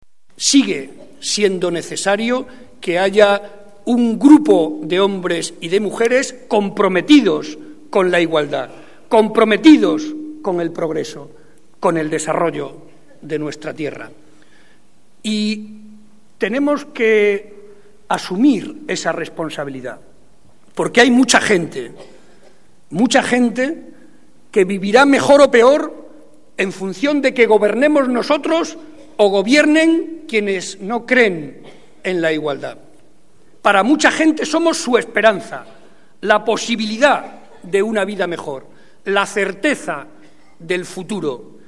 En el acto de celebración del centenario de la Agrupación de Puertollano